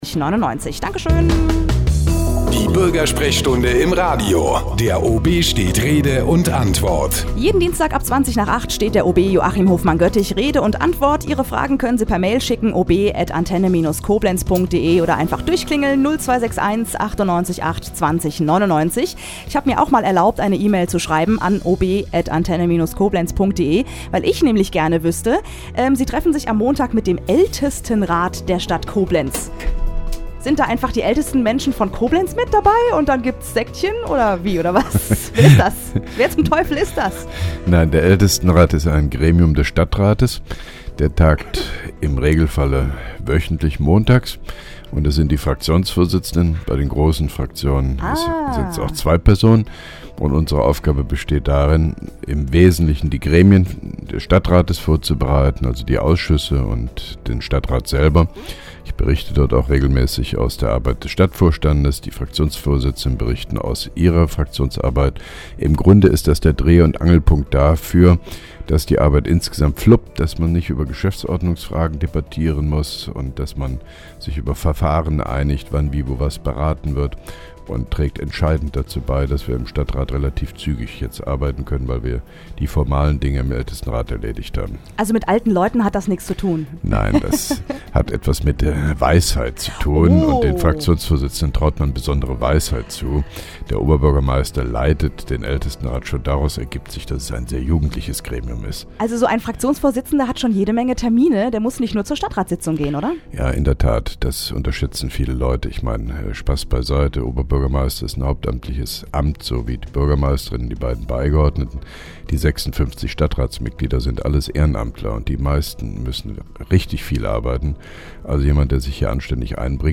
(5) Koblenzer OB Radio-Bürgersprechstunde 21.09.2010